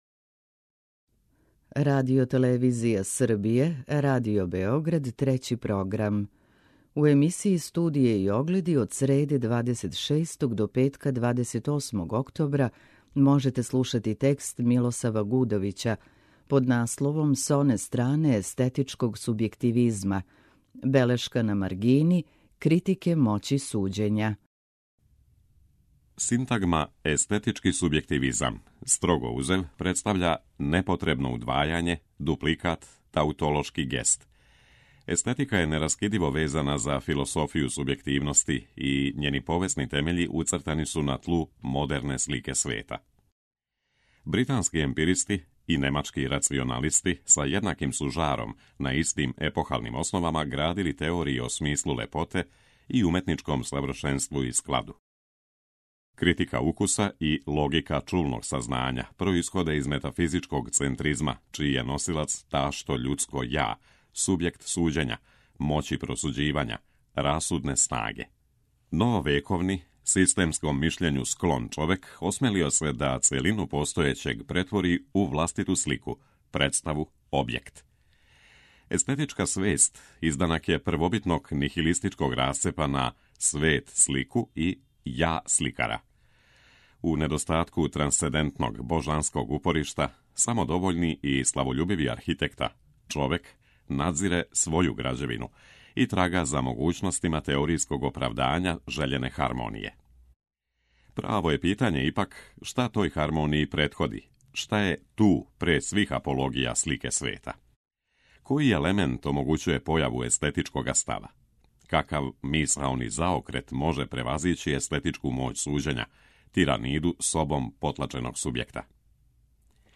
Белешка на маргини Критике моћи суђења”. преузми : 9.68 MB Тема недеље Autor: Редакција Прва говорна емисија сваке вечери од понедељка до петка.